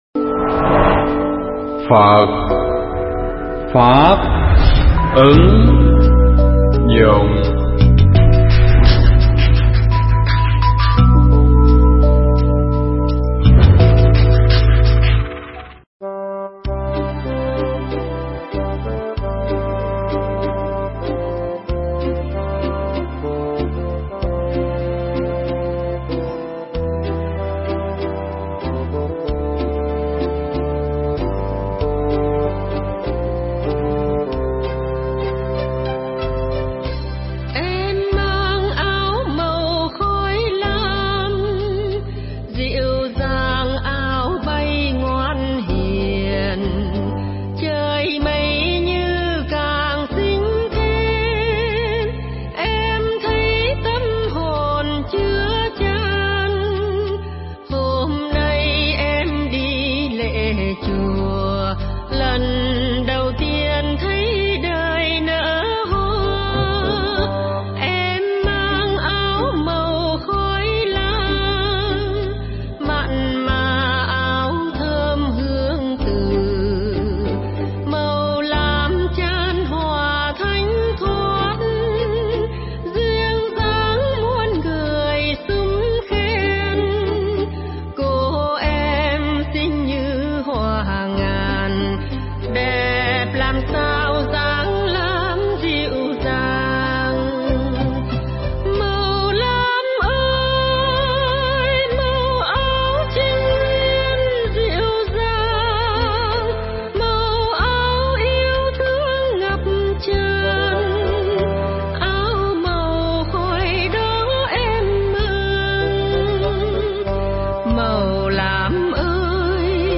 Nghe Mp3 thuyết pháp Vấn Đề Cúng Ma Chay Trong Đạo Phật